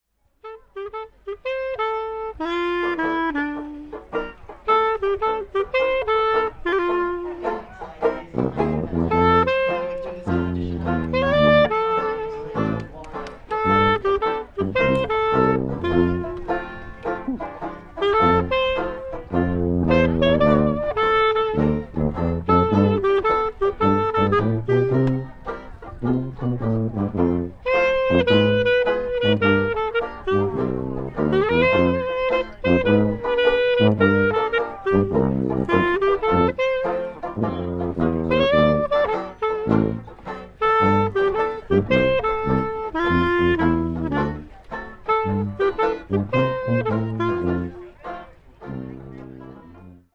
Plus my famous ‘hat microphone’ to capture the overall sound, which this time was fixed in with elastic for ease of adjustment and removal.
A Zoom H1 portable audio recorder, fixed into a straw hat by two pieces of elastic.
I haven’t yet assembled the separate recordings, but I can confirm that I managed to capture the sound of clarinet, banjo, sousaphone and hat for every single note of our walkaround sets, in crystal clear quality.
Hat: